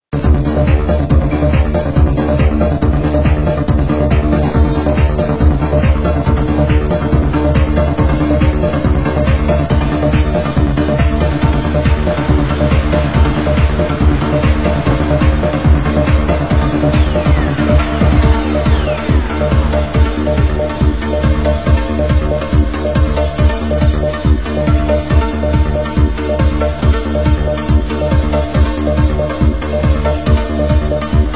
Genre: UK Techno